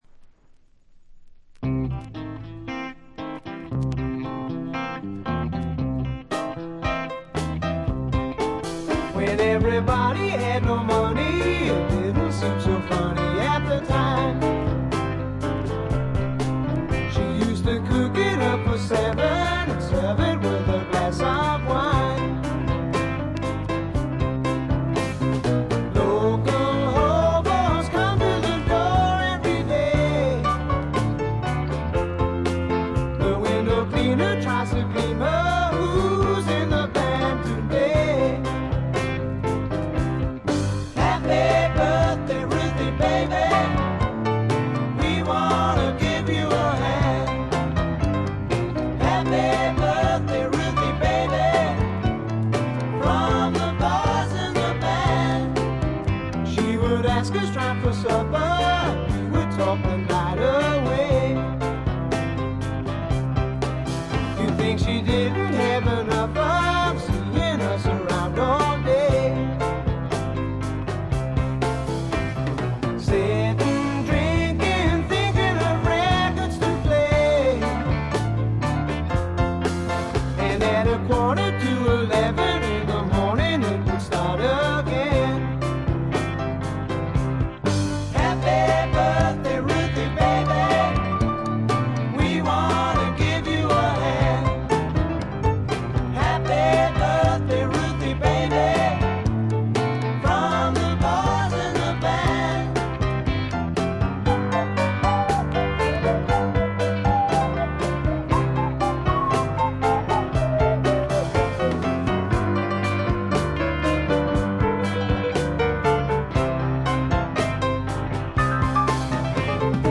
渋い英国産スワンプ、理想的な「イギリスのアメリカ」！